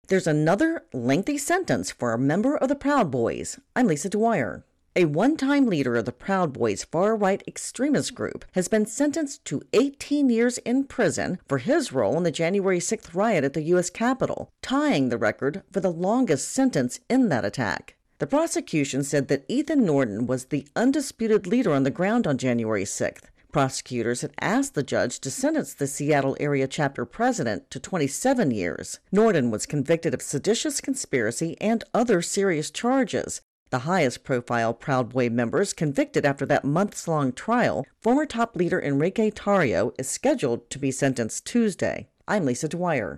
reports on Capitol Riot Proud Boys.